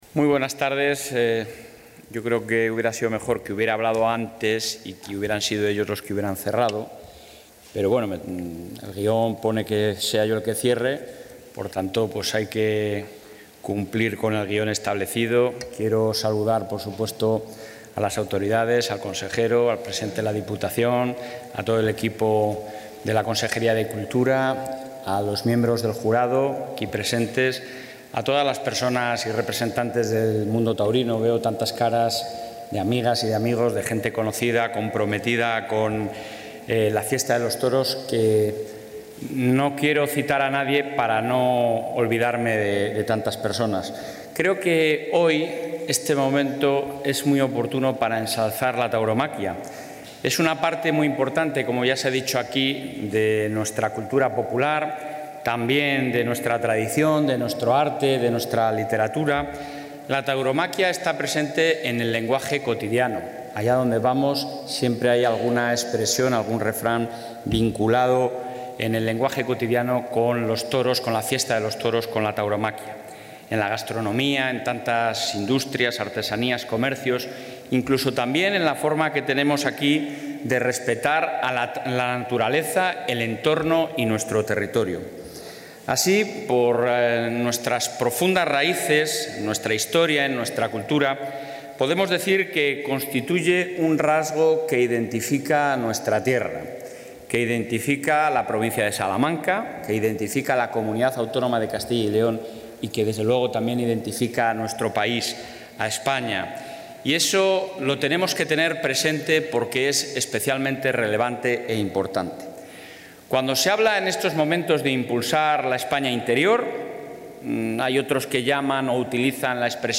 Durante el acto de entrega del Premio Tauromaquia de Castilla y León 2019 a la Escuela de Tauromaquia de Salamanca, el presidente del...
Intervención presidente.